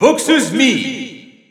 Announcer pronouncing Mii Brawler in French PAL
Mii_Brawler_French_EU_Announcer_SSBU.wav